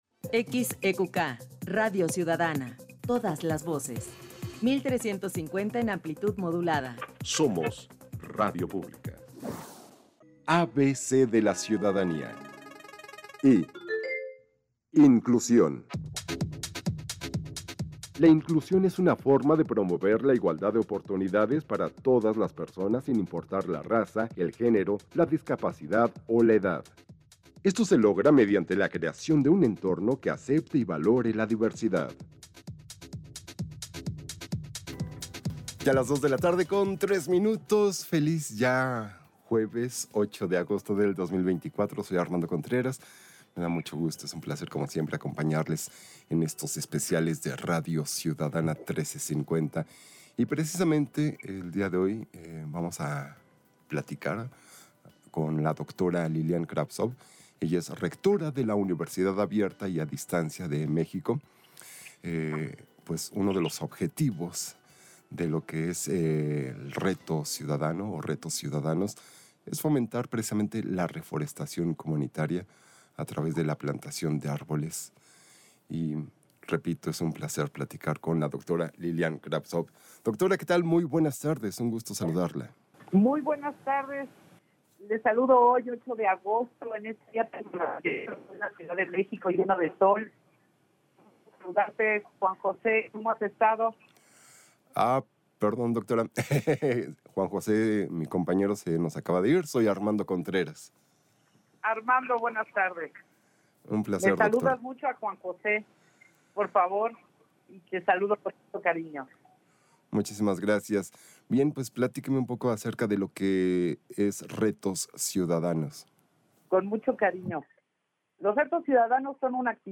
Entrevista
entrevista_IMER_Reto_22.mp3